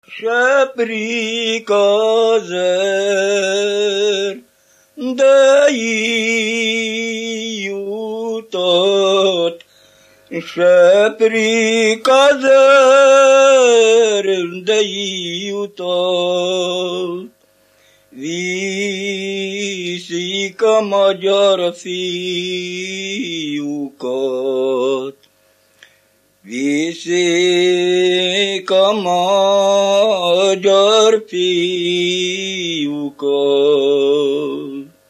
Erdély - Kolozs vm. - Visa
Műfaj: Katonadal
Stílus: 3. Pszalmodizáló stílusú dallamok
Kadencia: 4 (b3) VII 1